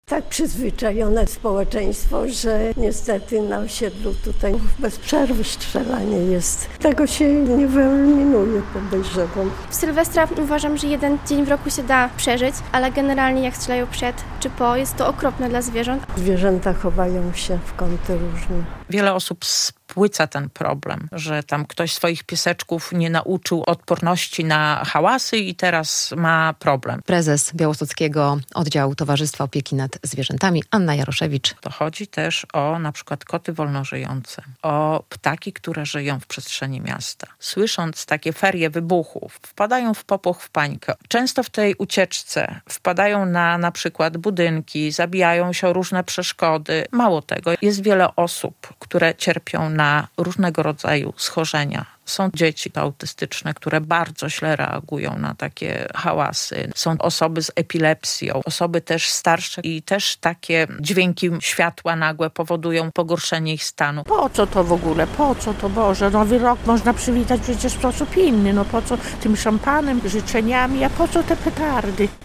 Czy możemy zrezygnować z fajerwerków w Sylwestra - relacja